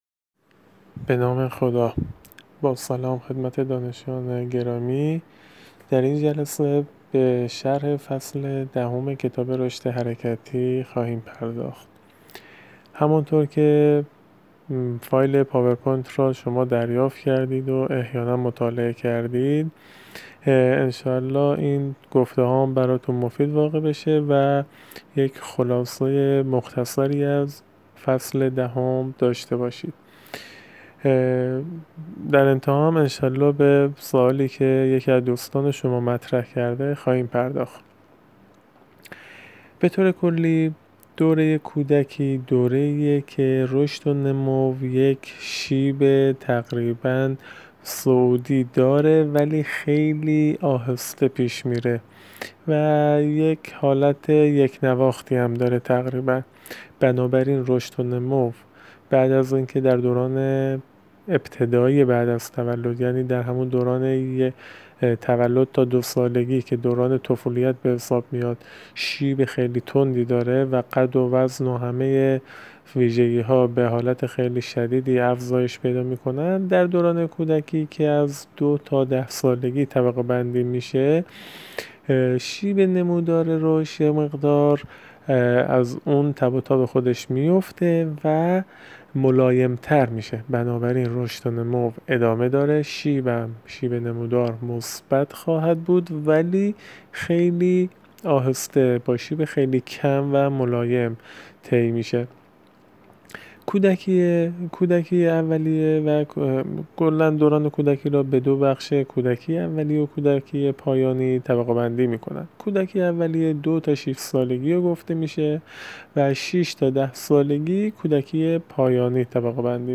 توضیحات شفاهی فصل دهم را از اینجا دانلود کنید.